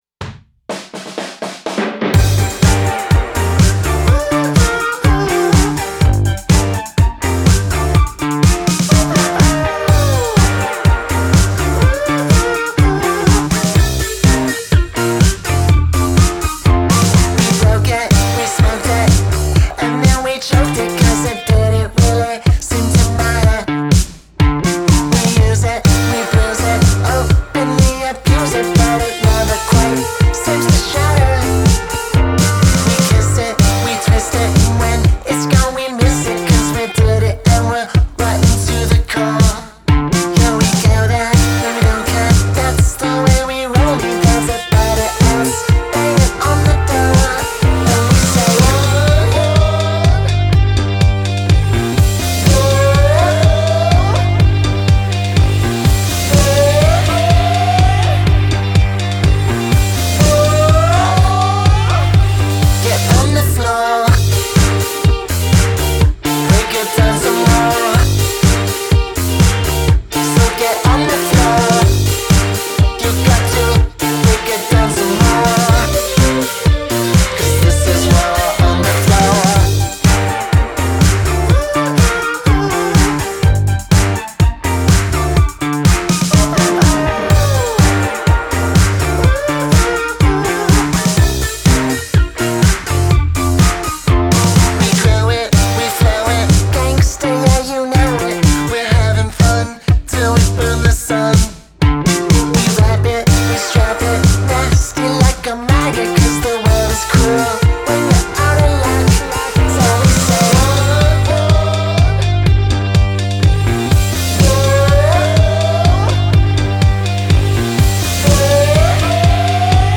Genre: Indie, Dance Punk